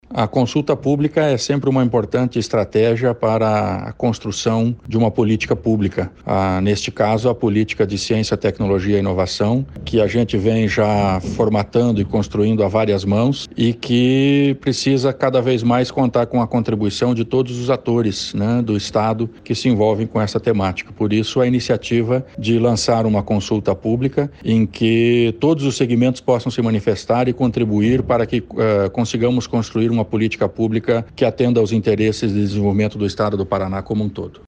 Sonora do secretário da Ciência, Tecnologia e Ensino Superior, Aldo Bona, sobre a abertura da consulta pública para política de ciência, tecnologia e inovação